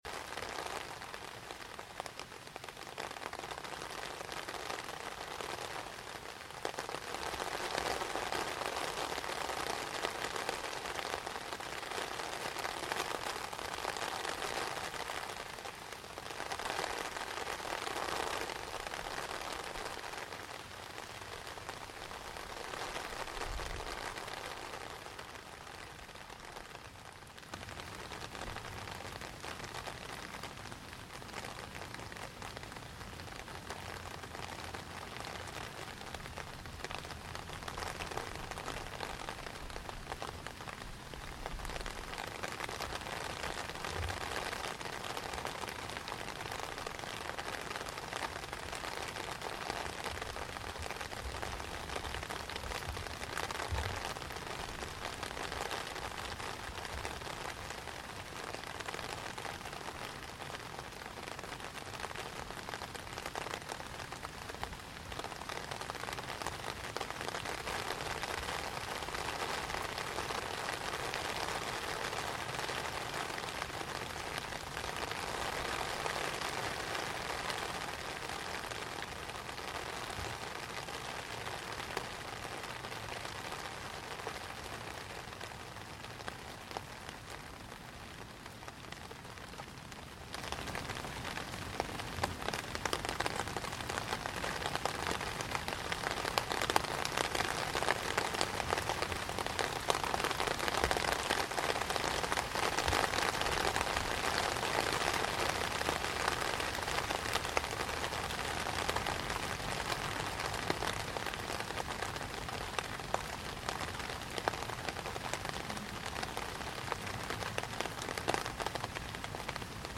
Garden Evening Rain on 19 July 2022, the hottest day recorded in the UK
Most of what you can hear is the rain falling onto the canopy of my swing seat.